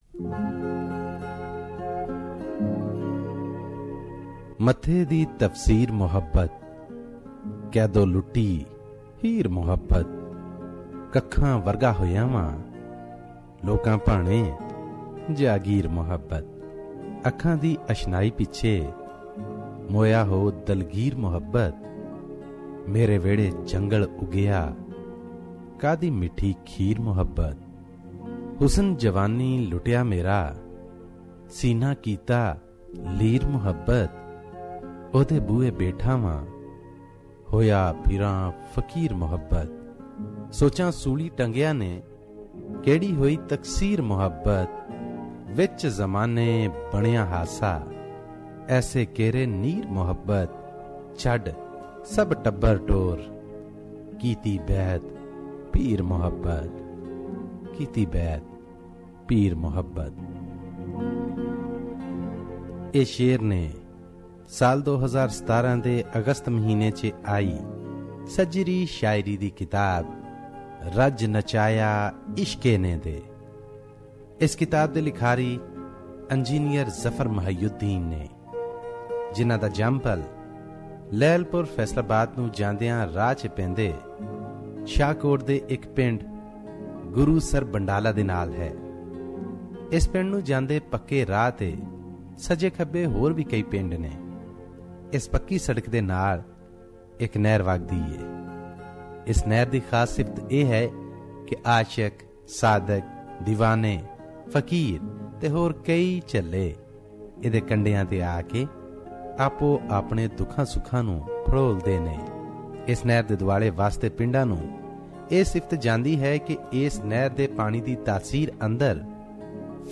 Lovely book review